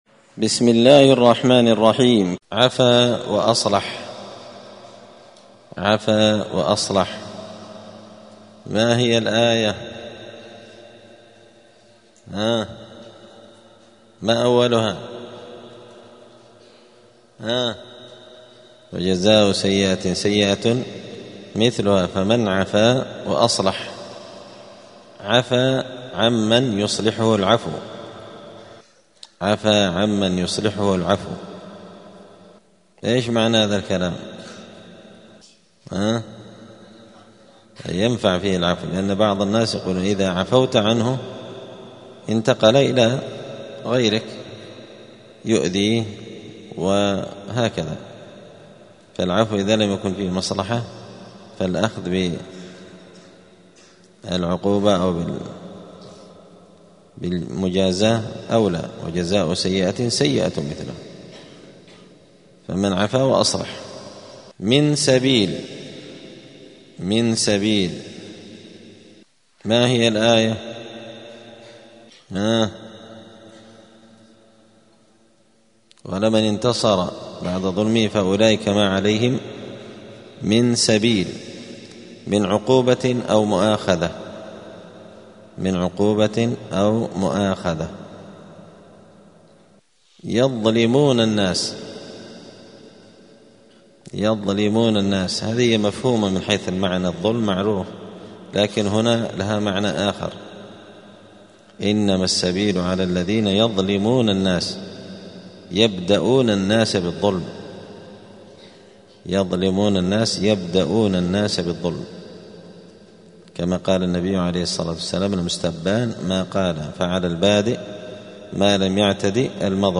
الأربعاء 24 جمادى الآخرة 1446 هــــ | الدروس، دروس القران وعلومة، زبدة الأقوال في غريب كلام المتعال | شارك بتعليقك | 27 المشاهدات